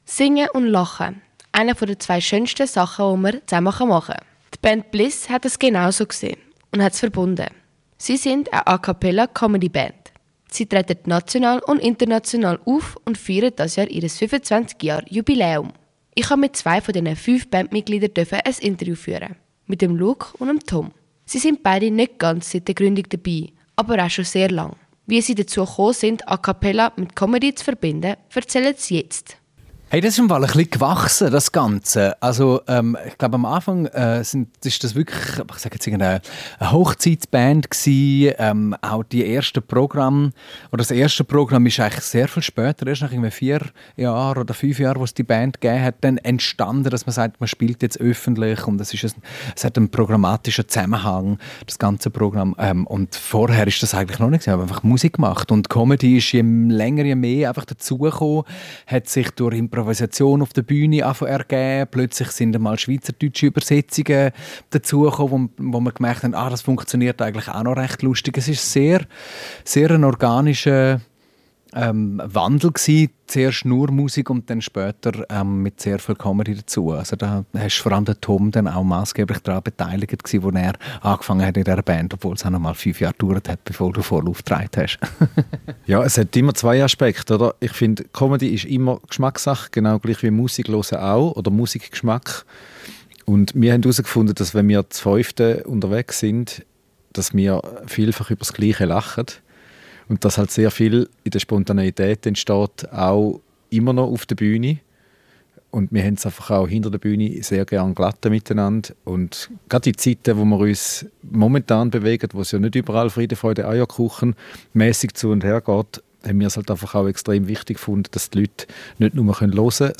Beschreibung vor 1 Jahr Seit fast 26 Jahren gibt es die A-cappella Comedy Band Bliss. Im Interview haben sie spannende Einblicke in ihre musikalische und humorvolle Welt gegeben. Sie haben Fragen zu ihrer einzigartigen Kombination aus A-cappella und Comedy beantwortet, ihre lustigsten Erlebnisse auf der Bühne und im Proberaum geteilt und erklärt, welche speziellen Techniken es im A-cappella-Gesang gibt.